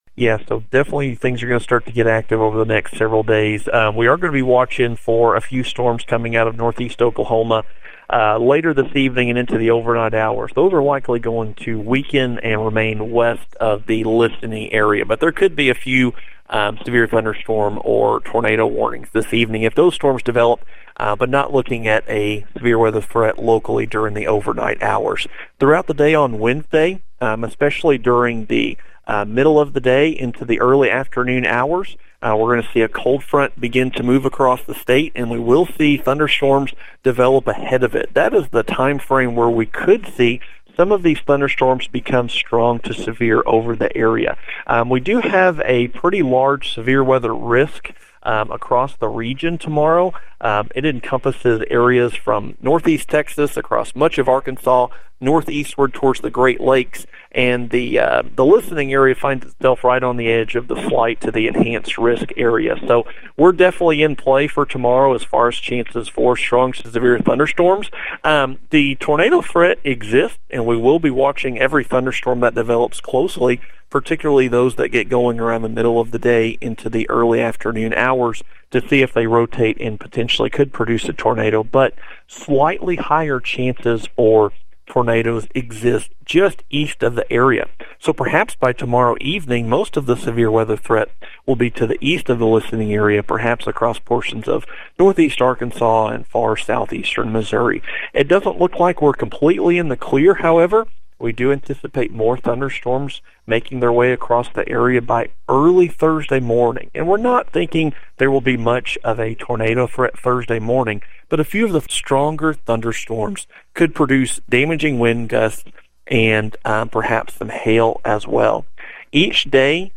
Tuesday evening severe weather update from NWS